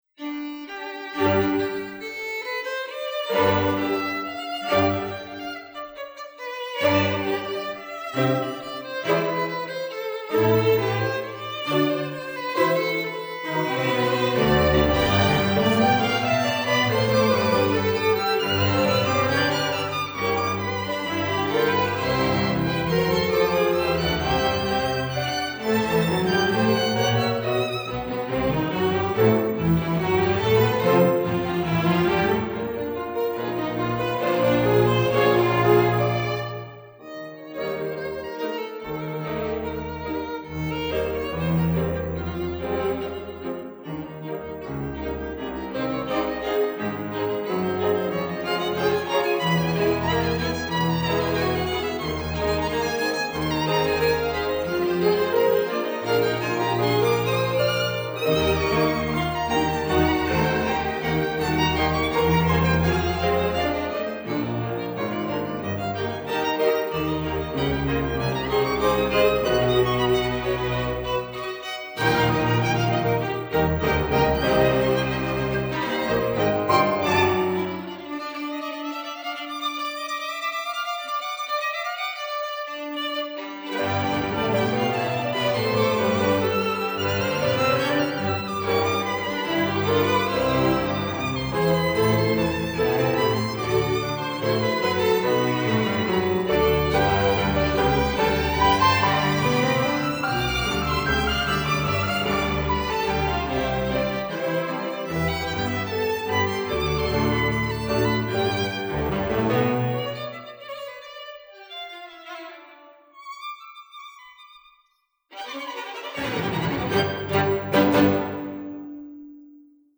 6Bughici_Allegreto.mp3